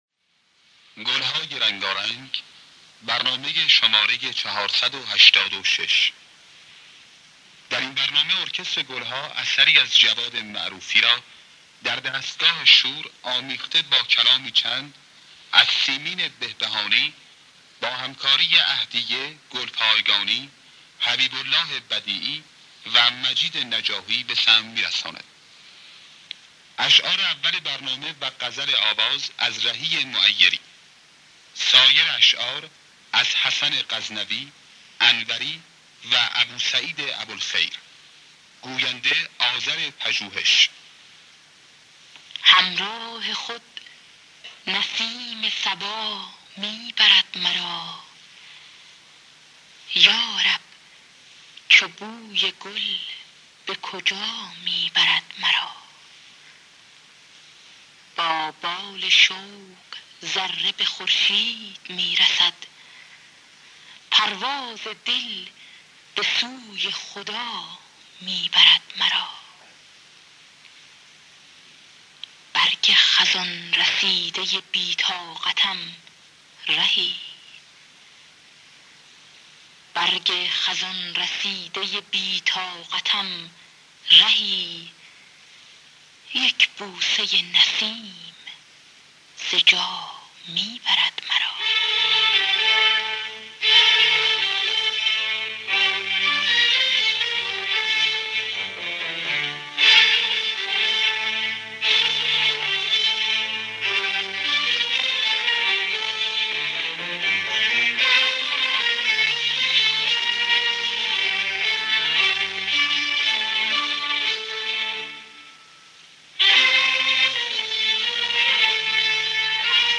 در دستگاه شور